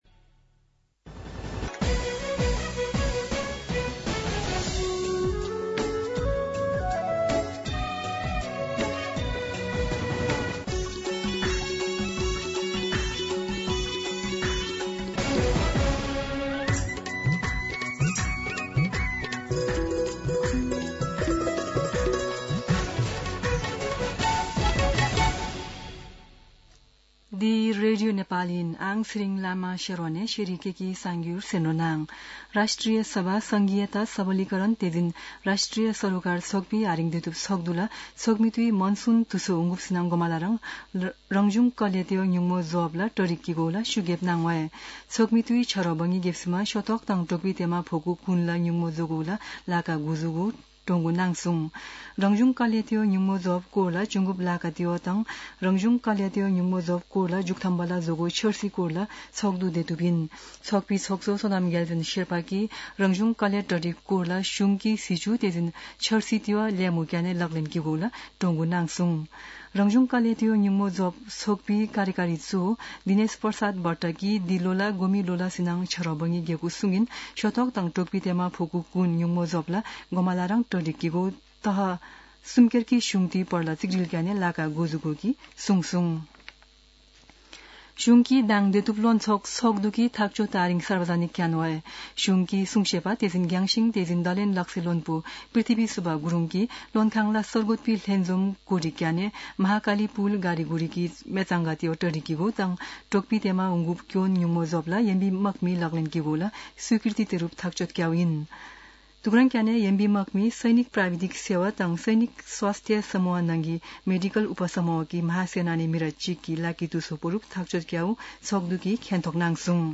शेर्पा भाषाको समाचार : ८ जेठ , २०८२